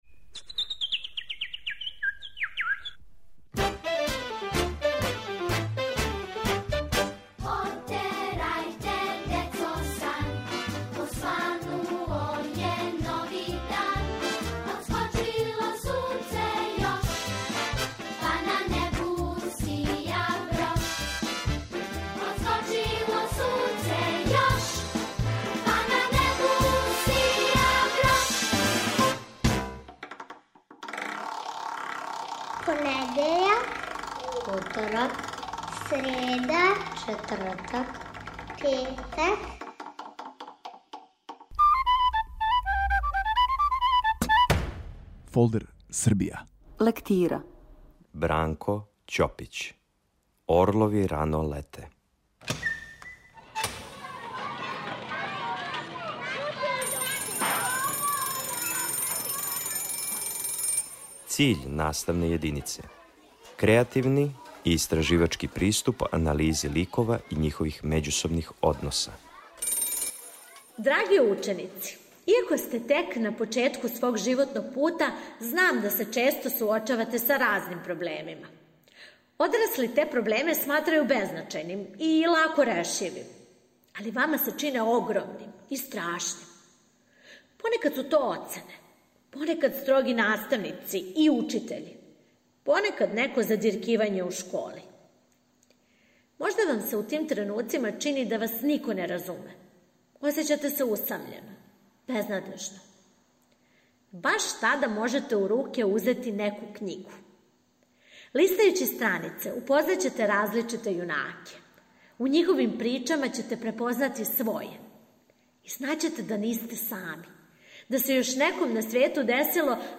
Серијал "Фолдер Србија" води вас на час српског језика и књижевности. Присуствујемо лекцији: Бранко Ћопић, "Орлови рано лете".